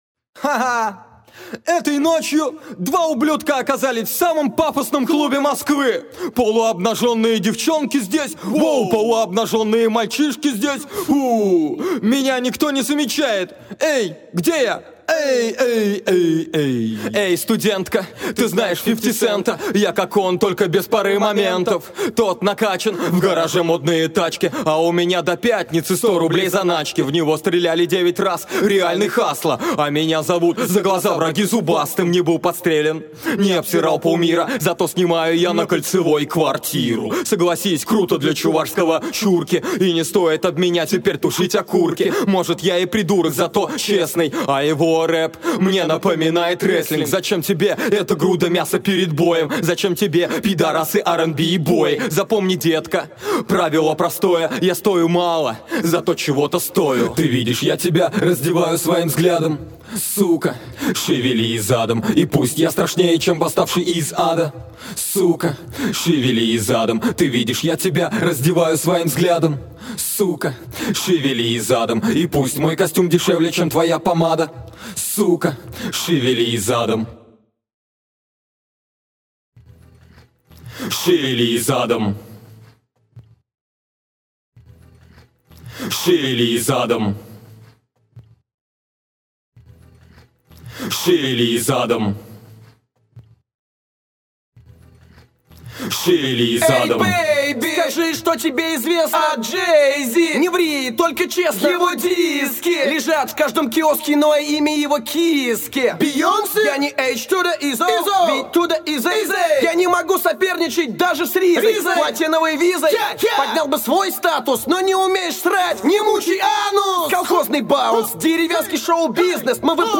Главная » Файлы » Акапеллы » Скачать Русские акапеллы